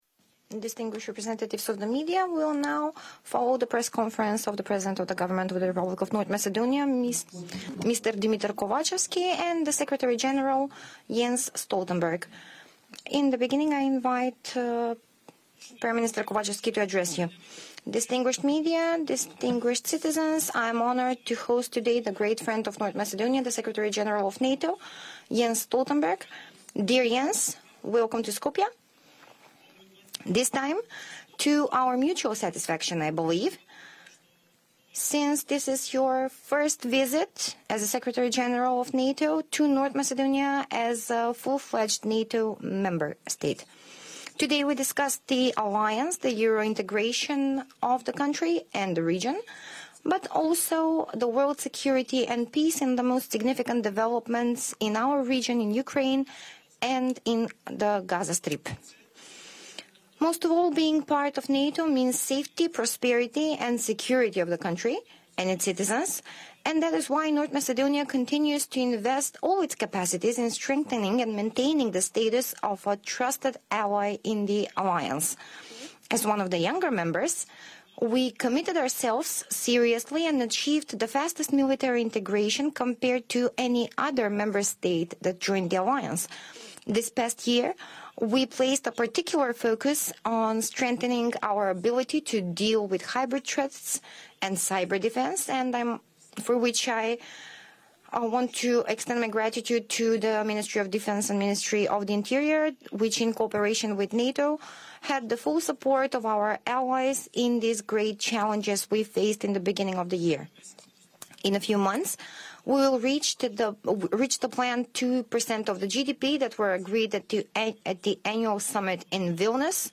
En visite à Skopje ce mardi (21 novembre 2023), le secrétaire général de l’OTAN, Jens Stoltenberg, s’est adressé à l’Assemblée de Macédoine du Nord pour la première fois depuis que ce pays a rejoint l’OTAN.
Address to the Assembly of the Republic of North Macedonia by NATO Secretary General Jens Stoltenberg